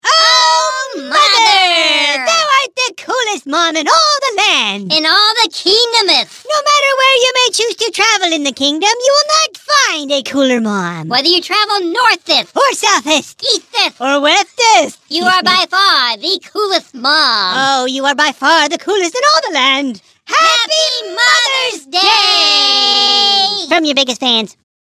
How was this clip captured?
Thou Art The Coolest Mom! is a hoops&yoyo greeting card with motion (later pop-up) and sound made for Mother's Day.